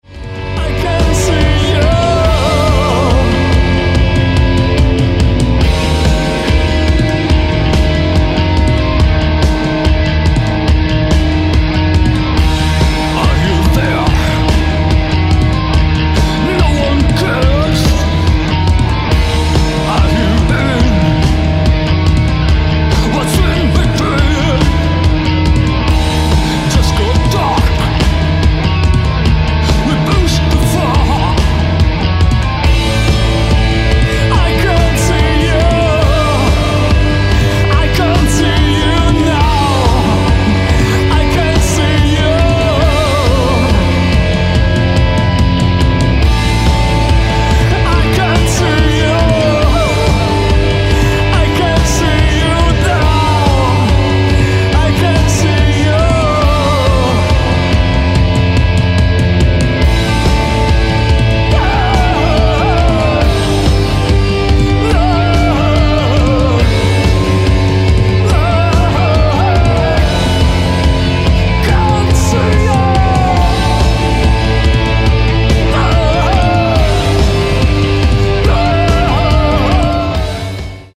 Batcave / Deathrock